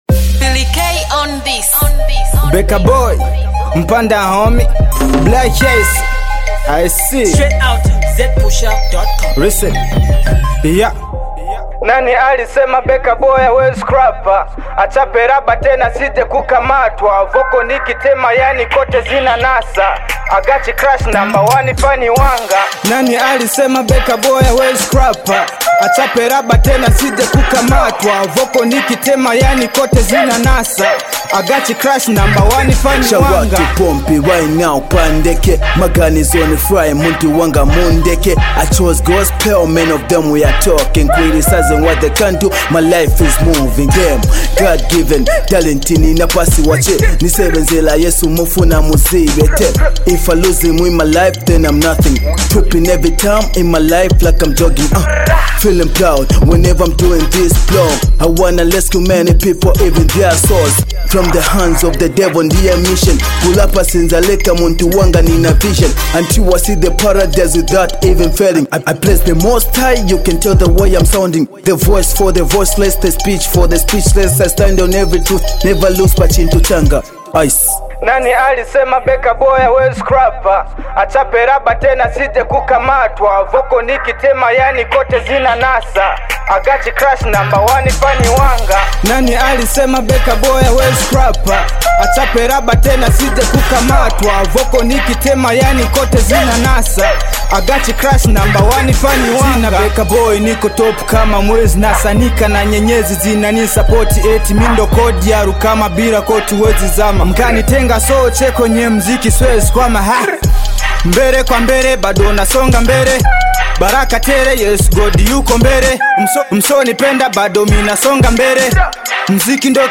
When the hip-hop genre is presented in Swahili
hip-hop joint